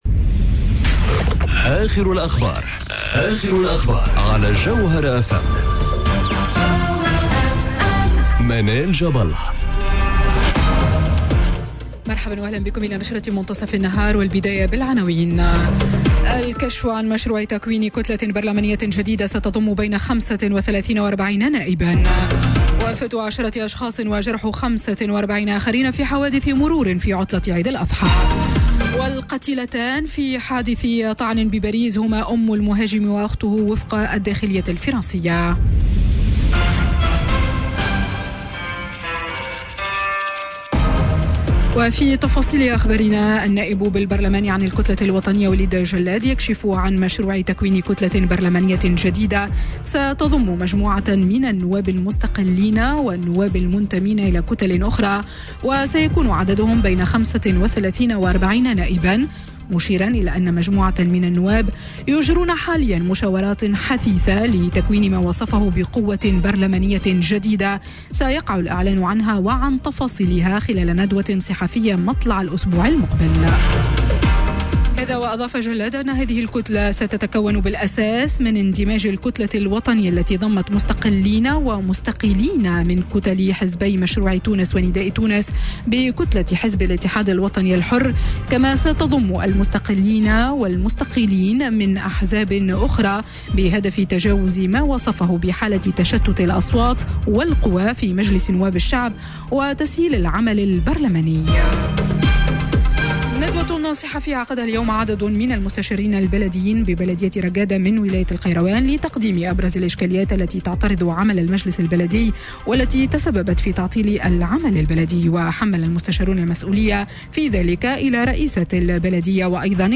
نشرة أخبار منتصف النهار ليوم الخميس 23 أوت 2018